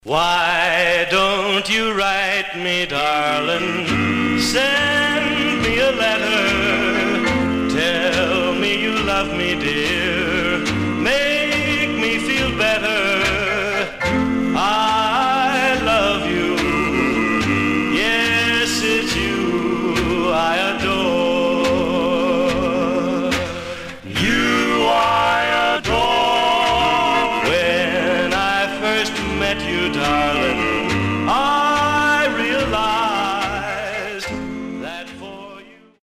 Condition: M- POP
Stereo/mono Mono